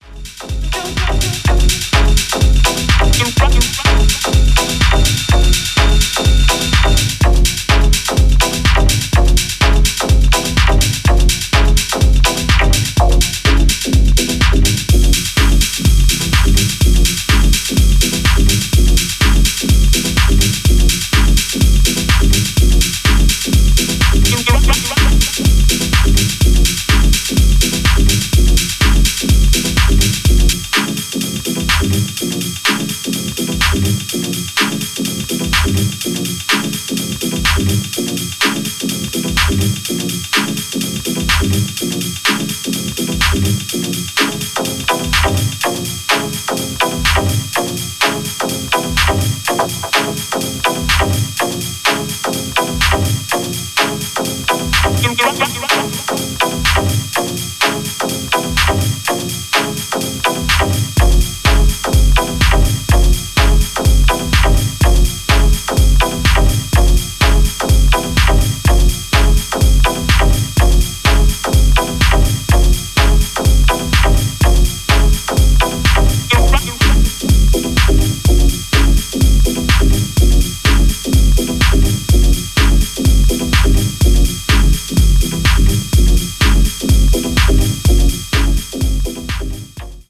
dub techno